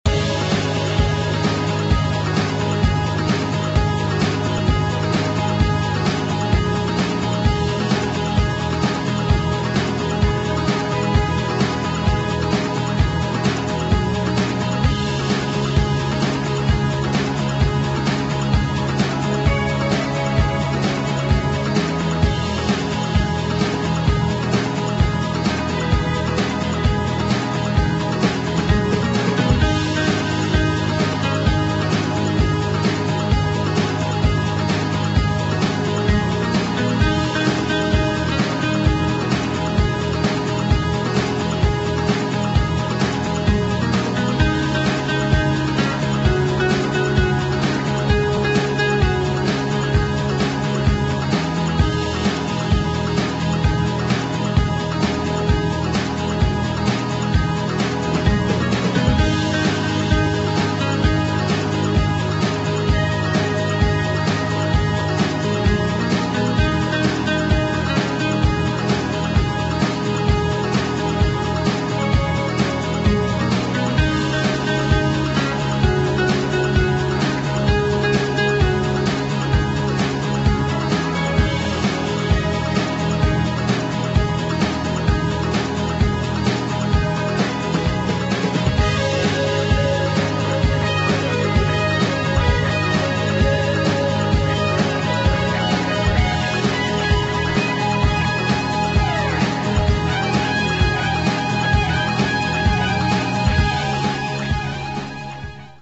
[ BREAKS ]